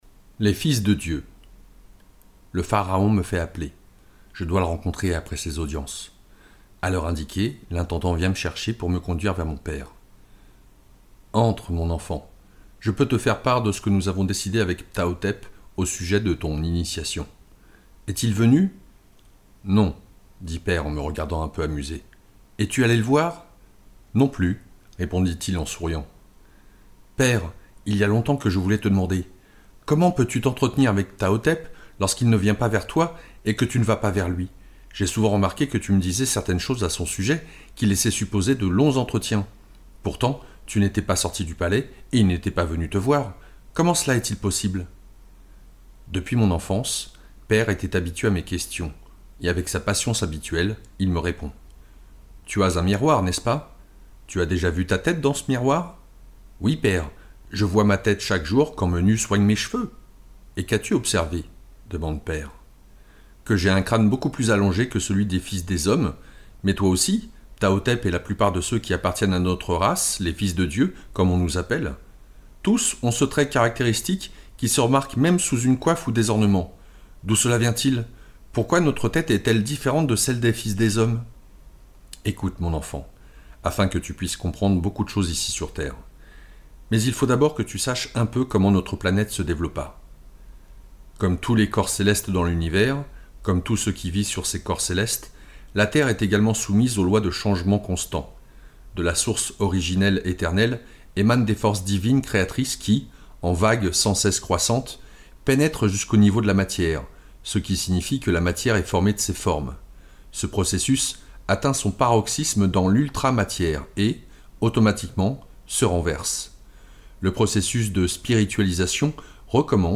Initiation - Livre audio - Hym Media
lecture